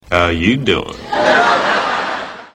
O mulherengo Joey (Matt LeBlanc) de FRIENDS fala seu bordão "how you doin'" ao paquerar mulher.
friends-joey-how-you-doin.mp3